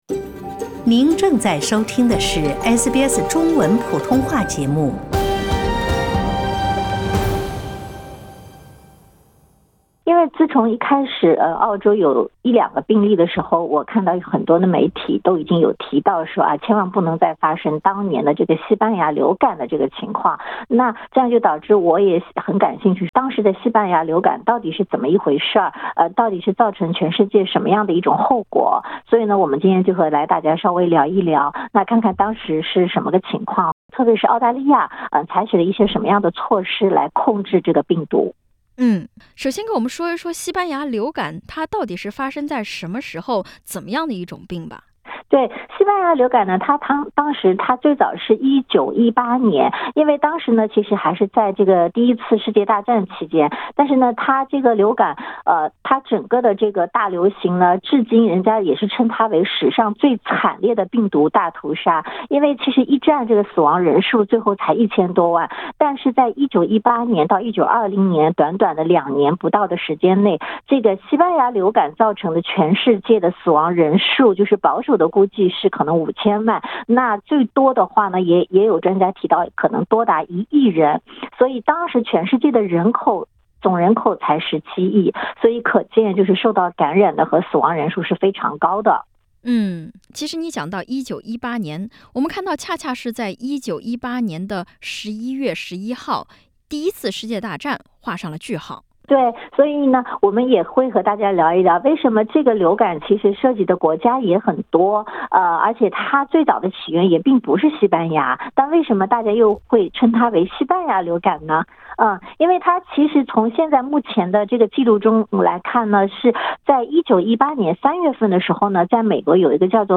百年前的澳大利亚是如何应对那场劫难的呢？对抗击新冠疫情有着怎样的启示？点击上方图片收听录音报道。